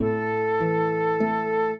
flute-harp
minuet7-3.wav